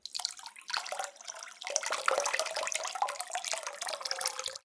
water-02
Category 🌿 Nature
bath bathroom bathtub bubble burp click drain drip sound effect free sound royalty free Nature